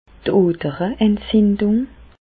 Bas Rhin d' Odereentzindùng
Ville Prononciation 67 Reichshoffen